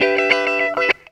GTR 46 EM.wav